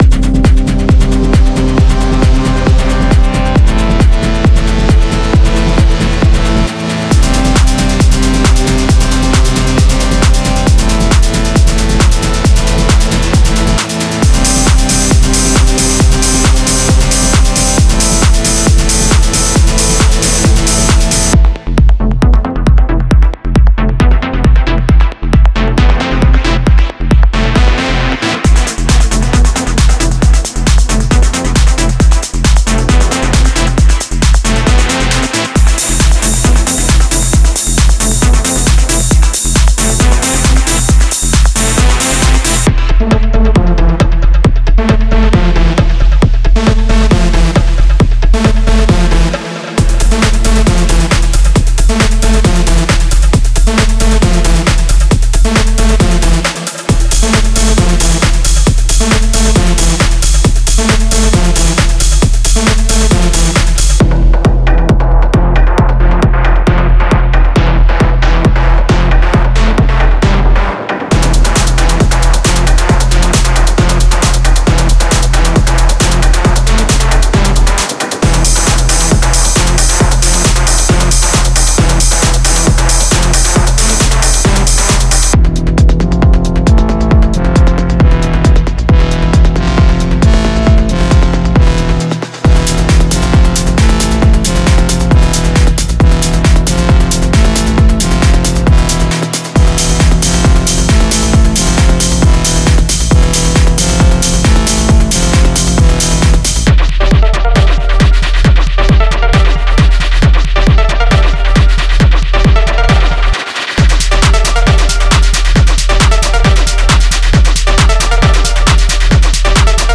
高品質なアナログ機材を用いて制作されており、このパックは有機的な温もりと触感のある存在感を持っています。
デモサウンドはコチラ↓
Genre:Industrial Techno
95 Drum loops (Full, Kick, Clap, Hihat, Perc, Ride)